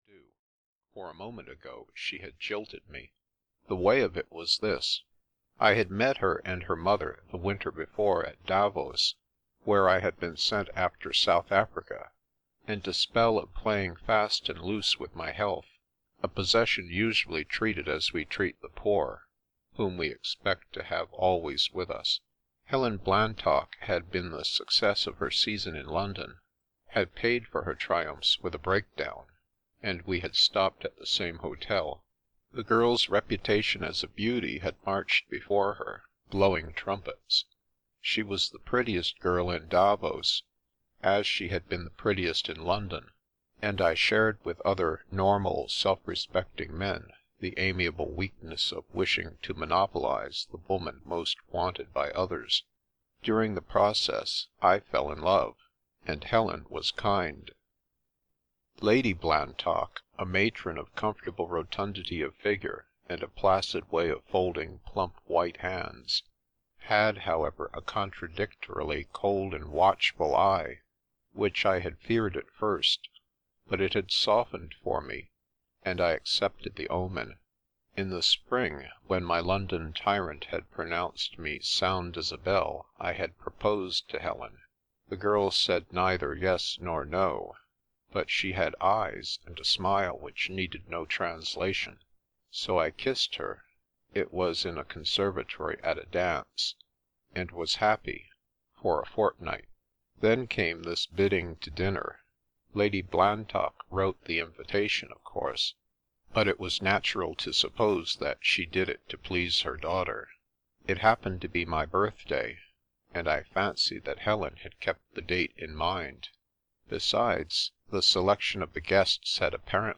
The Princess Passes (EN) audiokniha
Ukázka z knihy